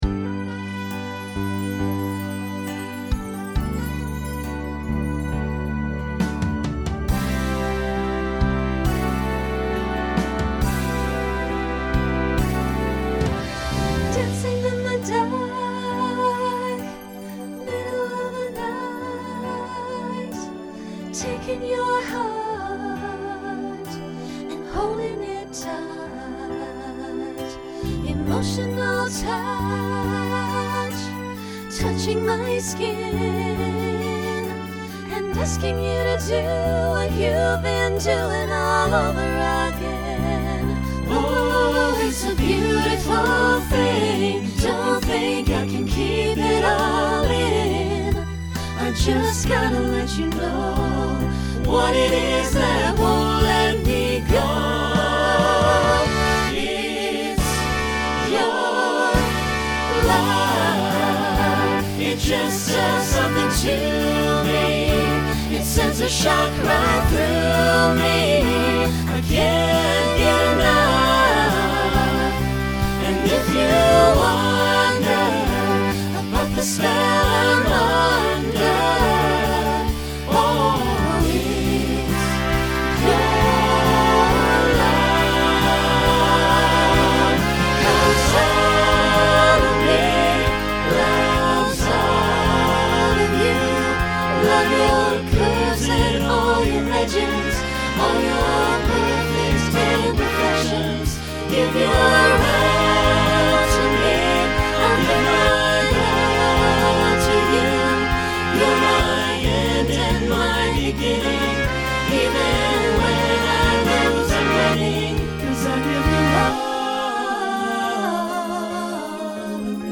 Country , Pop/Dance
Ballad Voicing SATB